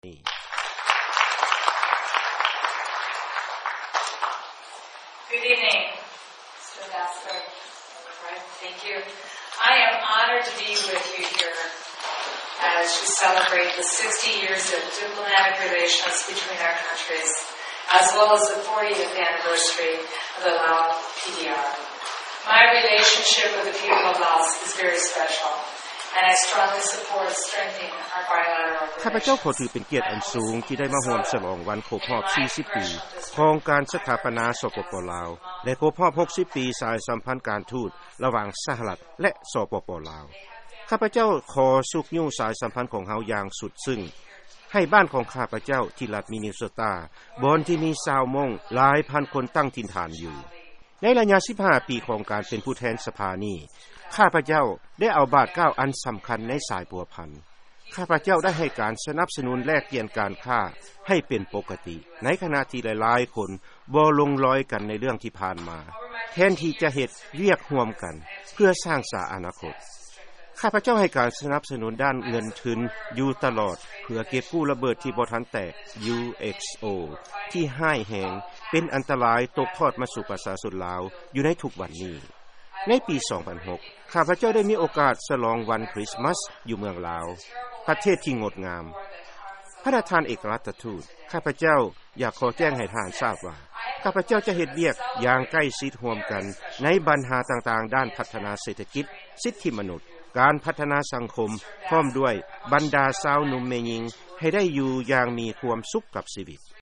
ເຊິນຟັງ ຄຳຖະແຫລງຂອງ ທ່ານນາງ Betty McCollum ຜູ້ແທນສະພາຕໍ່າ ຈາກລັດ Minnesota